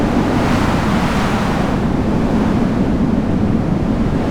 stormloop.wav